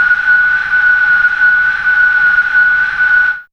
WWind.wav